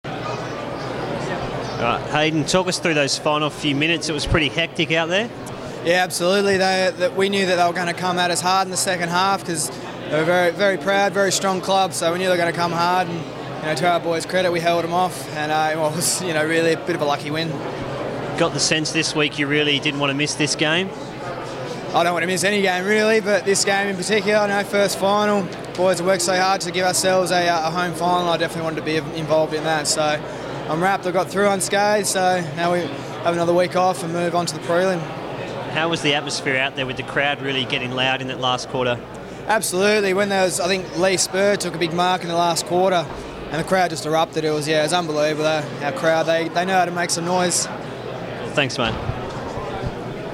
Hayden Ballantyne post-match - Qualifying Final v Sydney